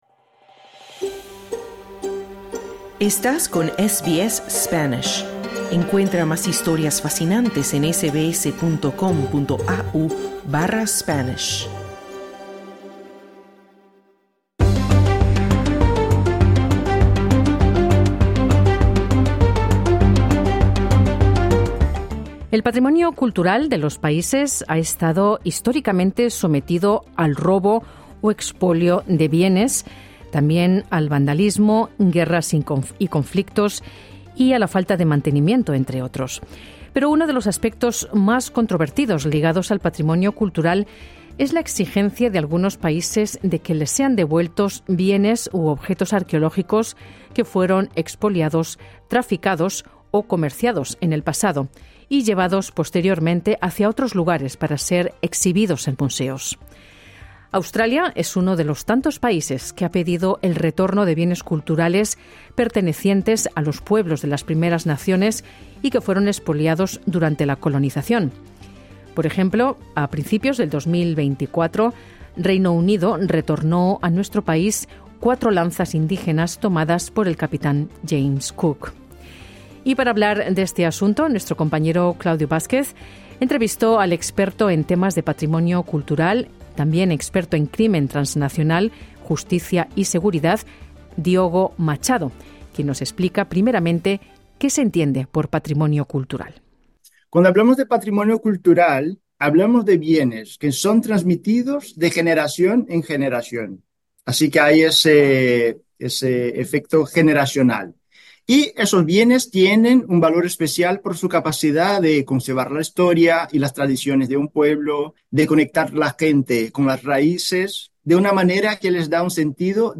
SBS en español